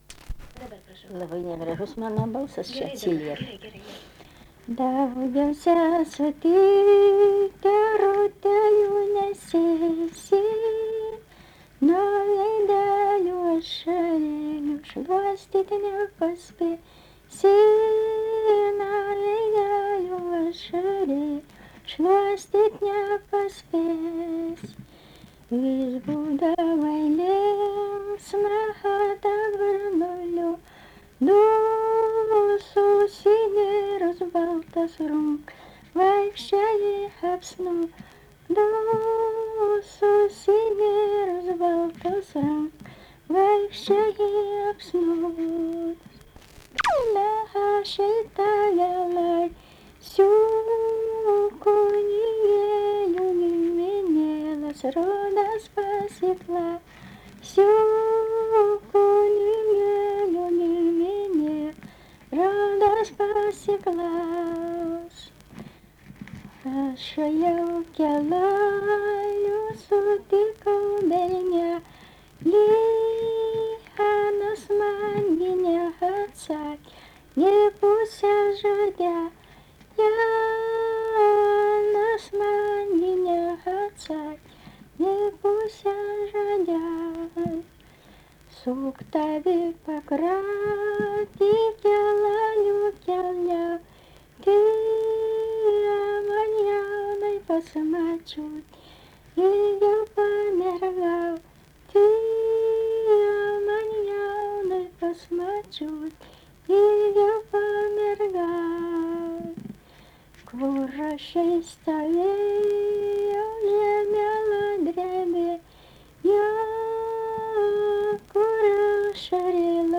daina, vestuvių
Antanašė
vokalinis